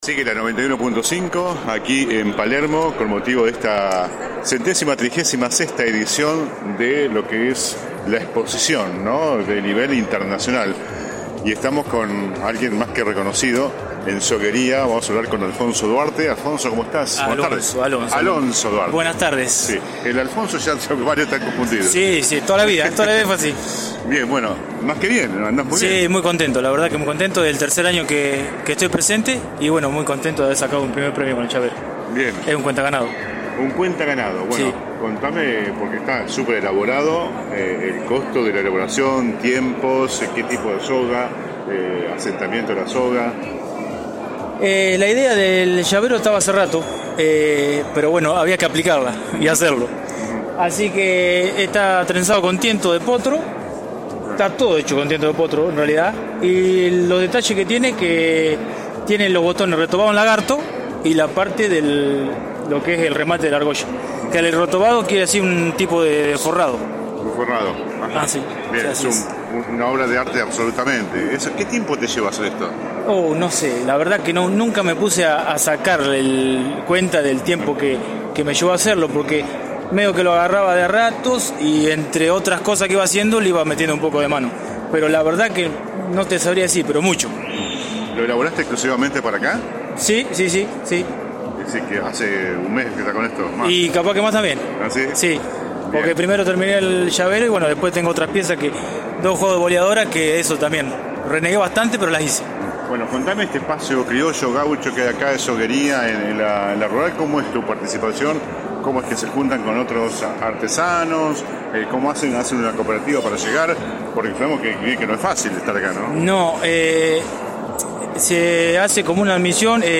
(galería de imágenes) La 91.5 continúa visitando este sábado la muestra del campo más importante a nivel nacional.